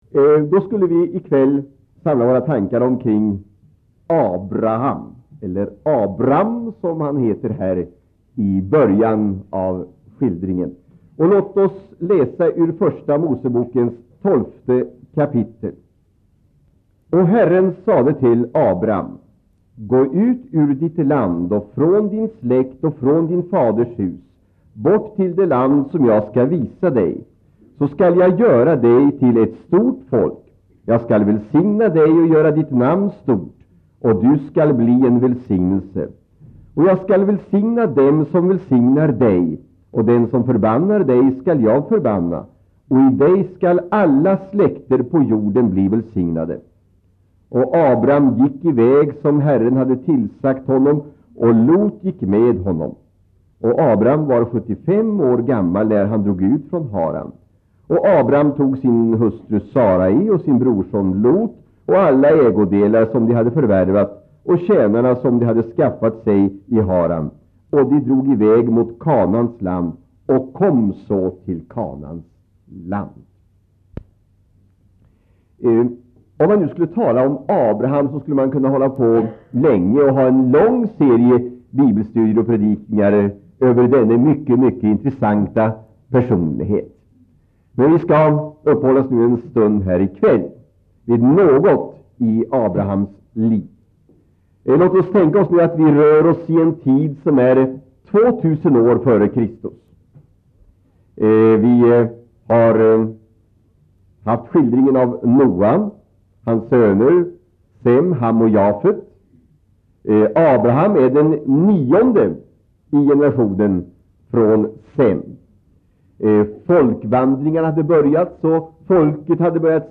Inspelad i Citykyrkan, Stockholm 1984-02-01.
Predikan får kopieras och spridas men inte läggas ut på nätet, redigeras eller säljas.